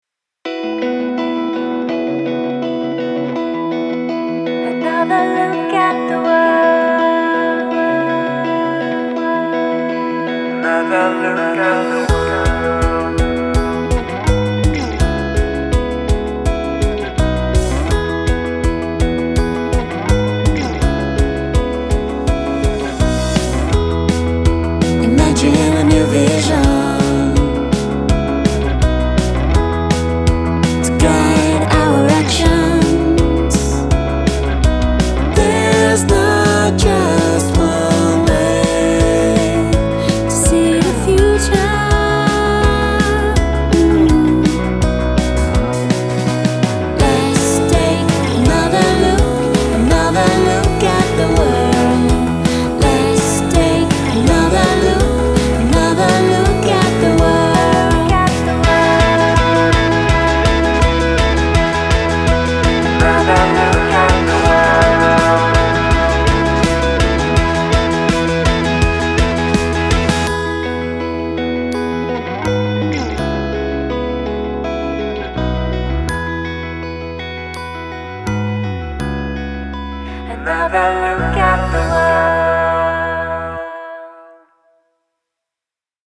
pop-song